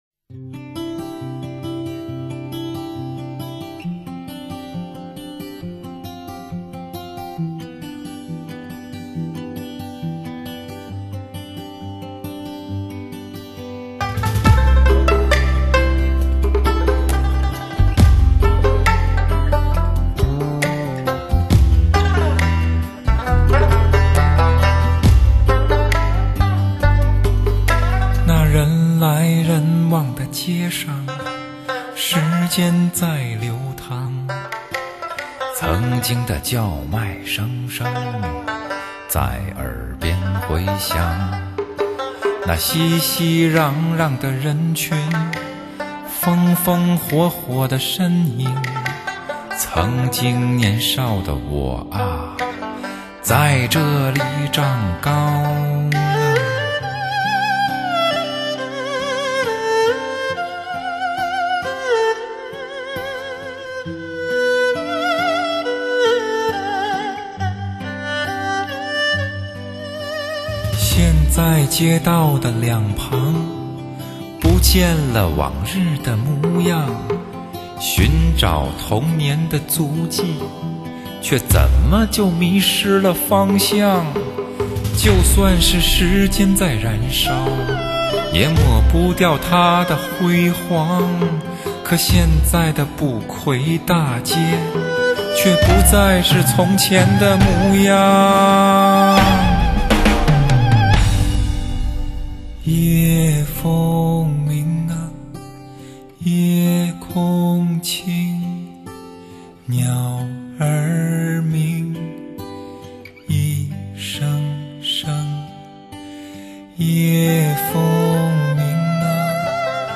低音特别浑厚圆润，音色更为通透明亮，音质表现更加细腻 ，